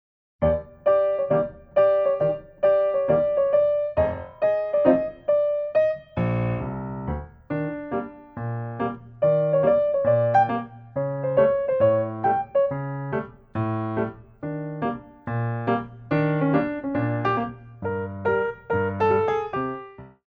By Pianist & Ballet Accompanist
Degagé